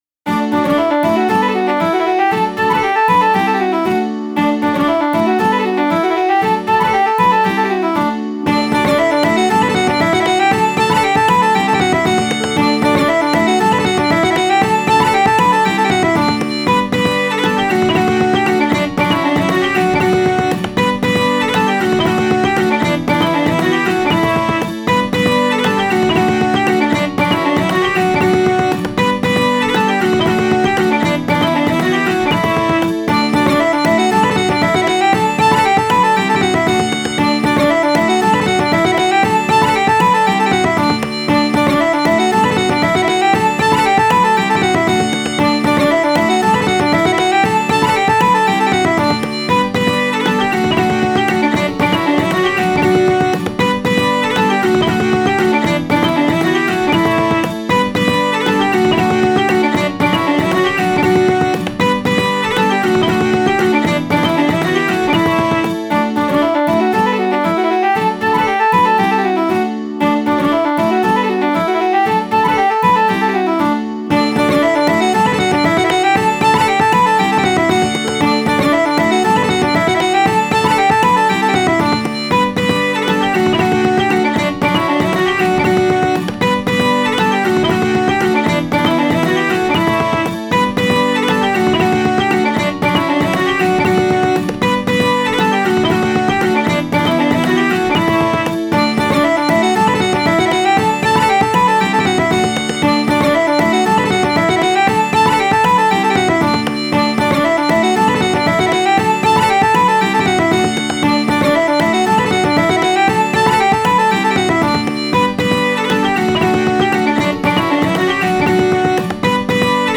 ケルト・ポルカ風味のせわしない曲です。 陽気な街の広場で流れてそうな感じです。
※過去作なので、サイト掲載曲の平均よりも音量が大きいです。